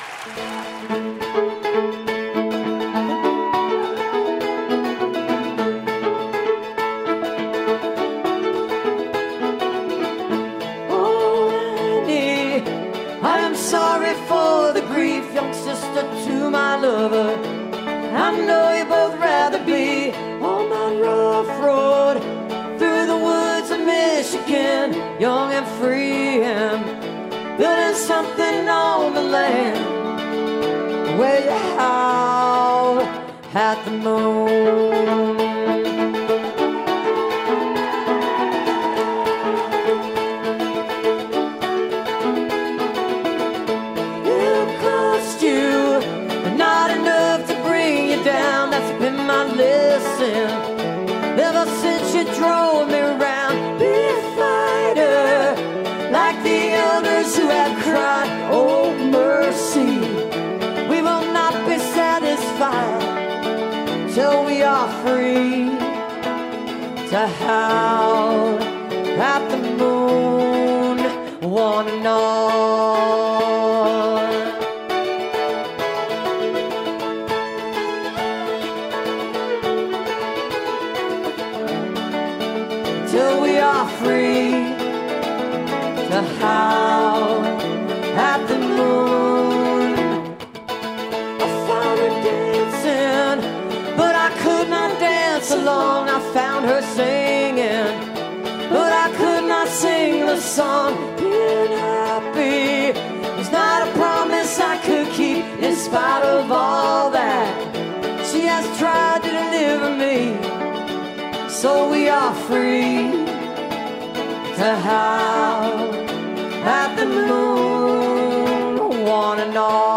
(captured from a youtube live stream)